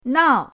nao4.mp3